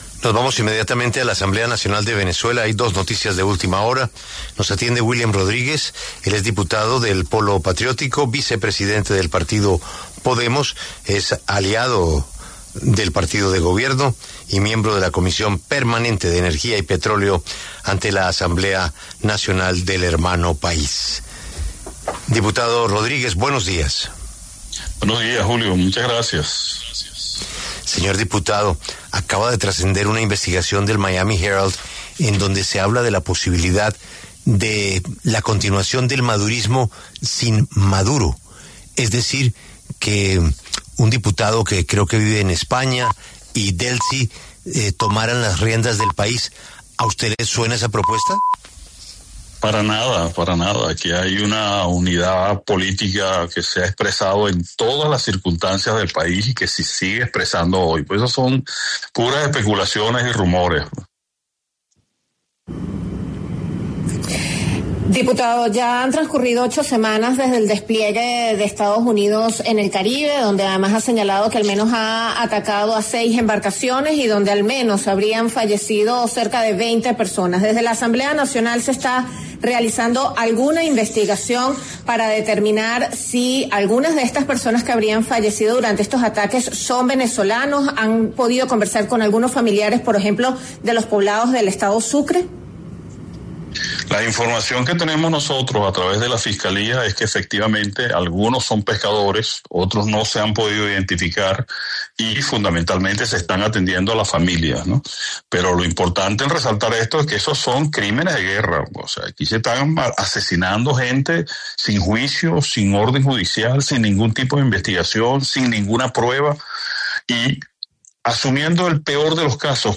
William Rodríguez, diputado del Polo Patriótico en la Asamblea Nacional de Venezuela, habló con La W, con Julio Sánchez Cristo, a propósito de la tensión entre Estados Unidos y el gobierno de Nicolás Maduro.